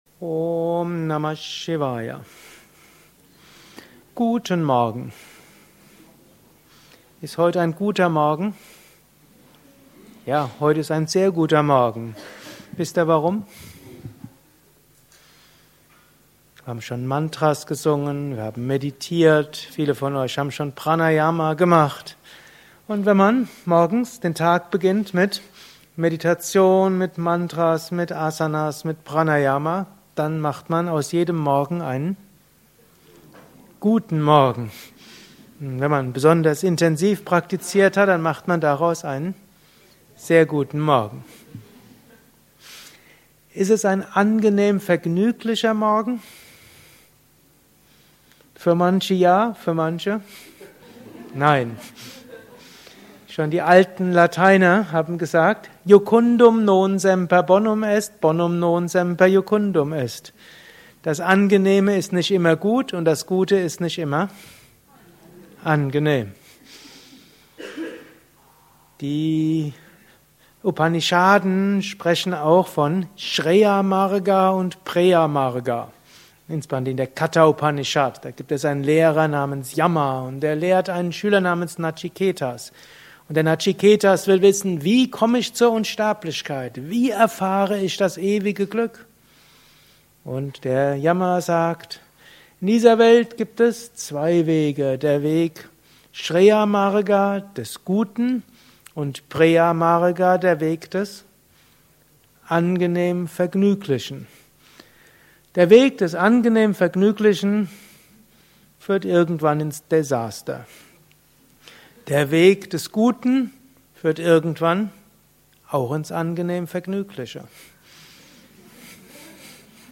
Gelesen im Anschluss nach einer Meditation im Haus Yoga Vidya Bad Meinberg.
Lausche einem Vortrag über: Ist heute ein guter Morgen